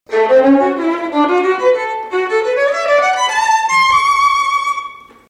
Violine (Geige)
Der Klang der Violine ist eher hell, sie spielt vor allem in den höheren Lagen und ist die „Sopranstimme“ der Familie der Streichinstrumente.
Violine.mp3